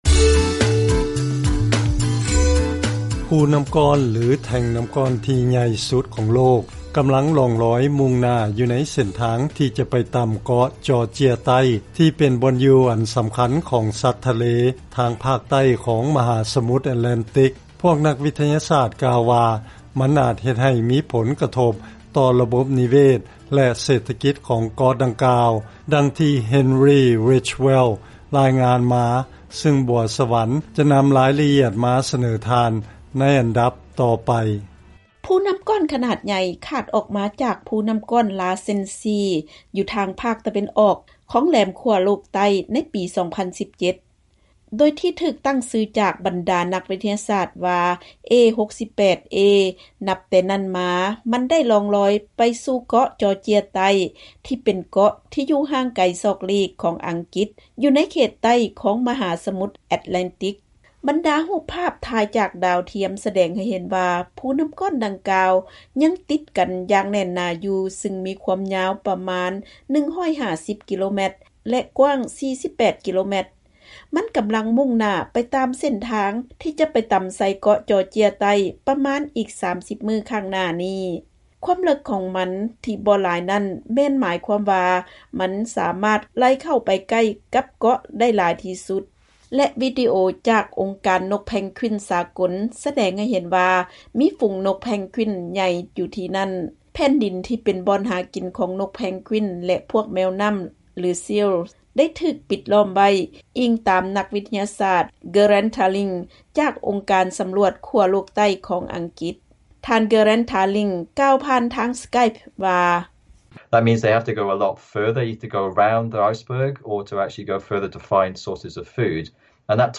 ເຊີນຟັງລາຍງານກ່ຽວກັບພູນໍ້າກ້ອນຈະແລ່ນຕໍາໃສ່ເກາະຈໍເຈຍໃຕ້